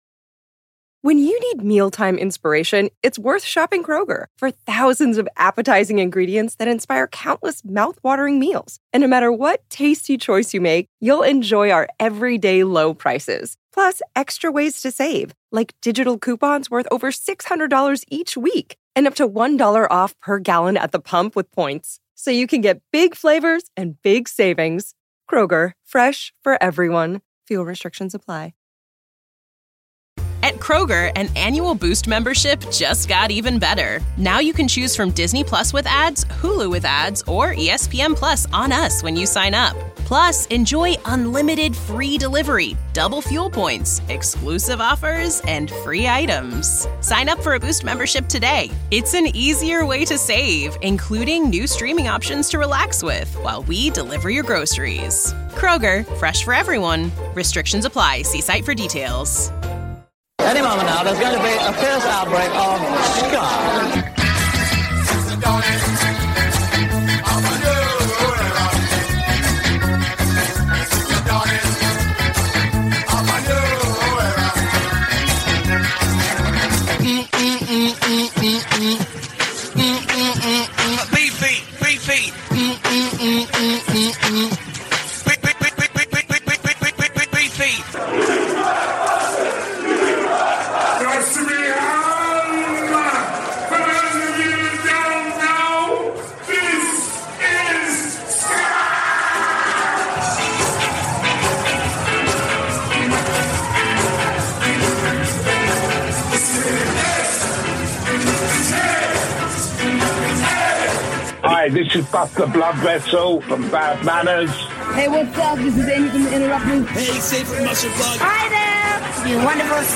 **THE WORLD'S #1 SKA SHOW - SKA NATION RADIO - FOR YOUR LISTENING PLEASURE **